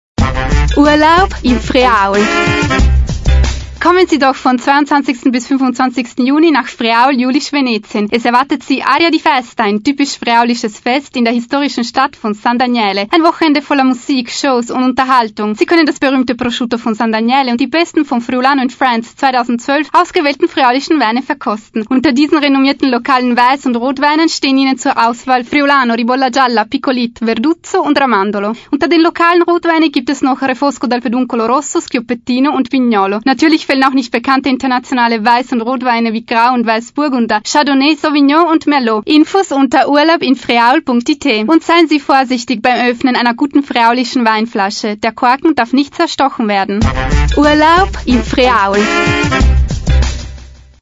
Spot-Audio (
urlaub-in-friaul_Aria-di-Festa_Kronehit_spot1_48kbps.mp3